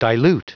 Prononciation du mot dilute en anglais (fichier audio)
Prononciation du mot : dilute